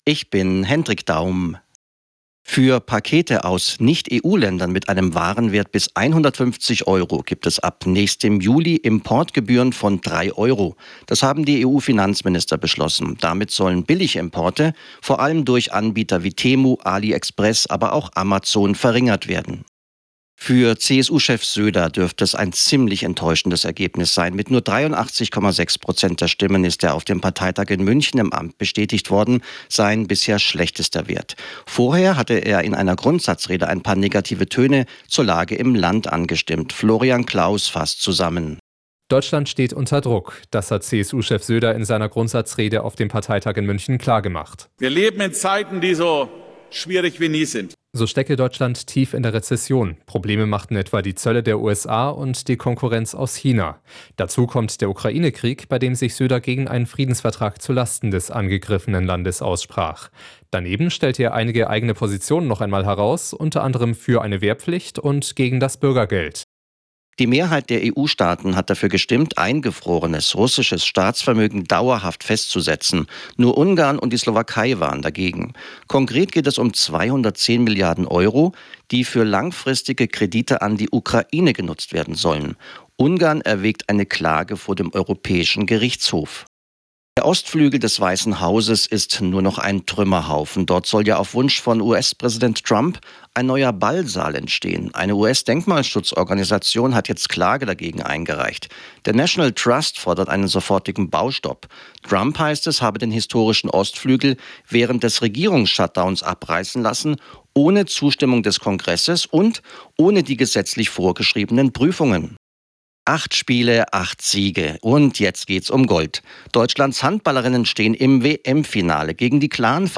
Die aktuellen Nachrichten zum Nachhören